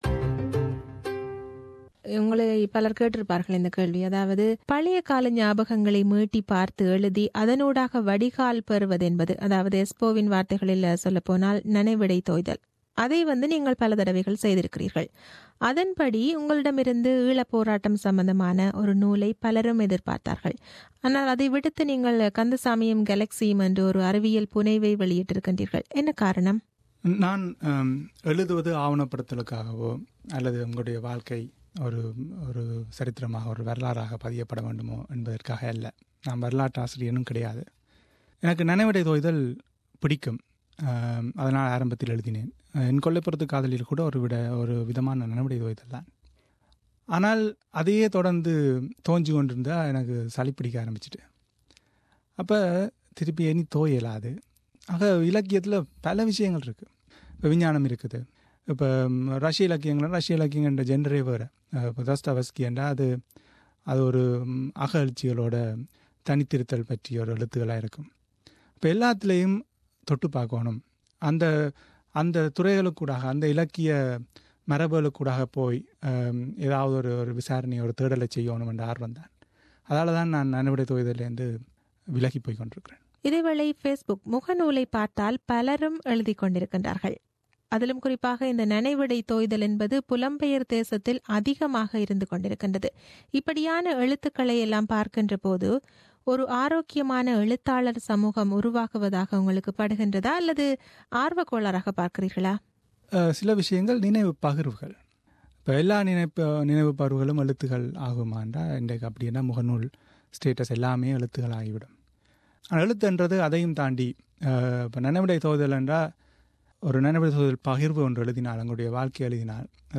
ஒரு சந்திப்பு பாகம்- 02